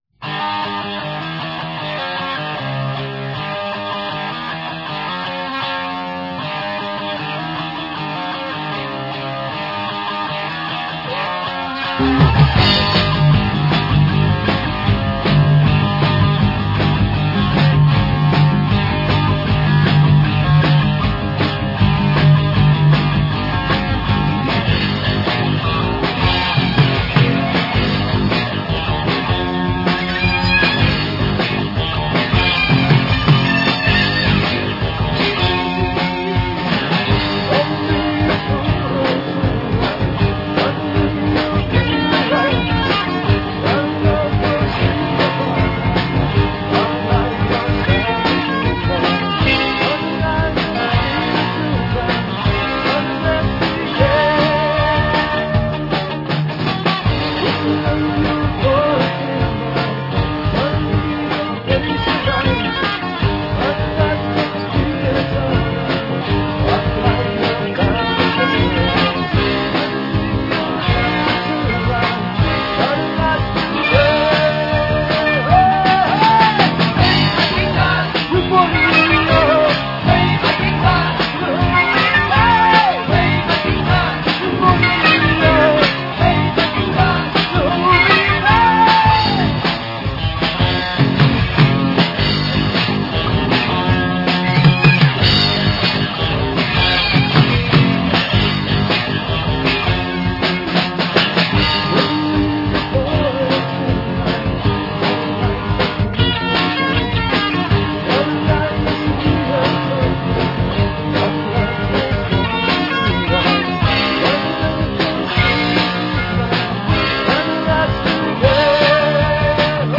• 录demo时歌词尚未完成，